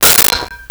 Metal Lid 05
Metal Lid 05.wav